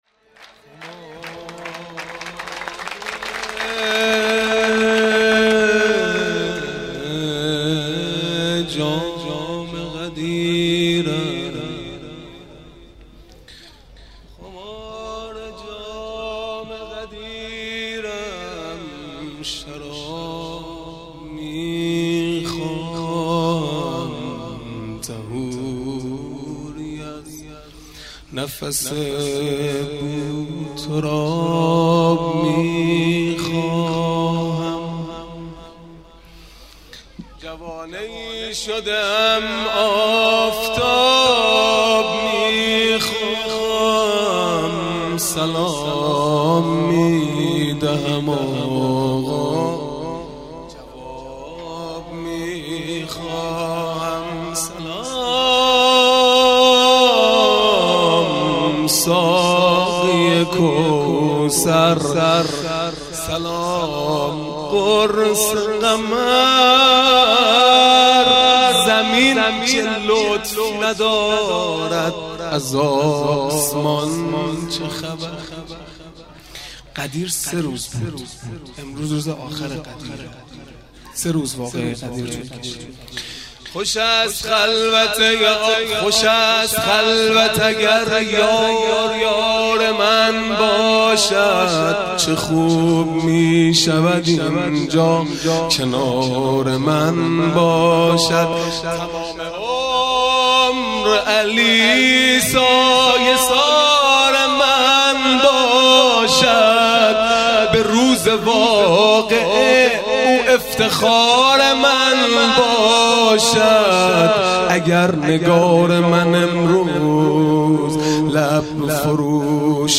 مدح | خمار جام غدیرم شراب میخواهم
جلسۀ مشترک هیئات و مجموعه های دانش آموزی(حسینیه ام الأئمه) | 31 مرداد 1398